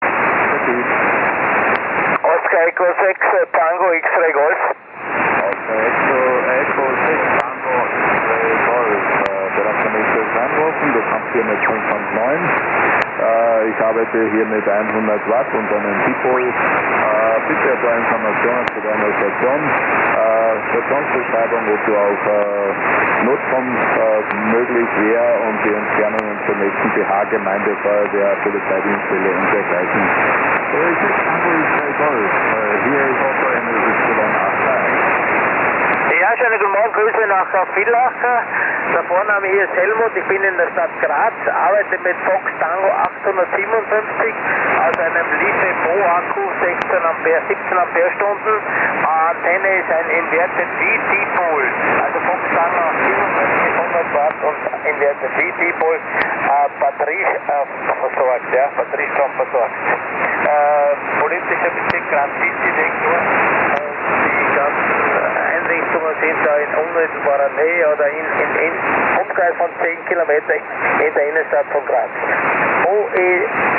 On the 1st of May of every year (or other special days), many austrian 'official' stations work with amateur stations in the 80m and 30m amateur bands, to be prepared for creating a fast and effective emergency network. Most of the stations are from the military, from the red cross, or from regional administrations.Those 2 amateur bands are selected, because they are officially allocoted to both amateur stations and fixed and mobile radio services, to be in full accordance with international regulations.
...from the annual 'all austria contest'...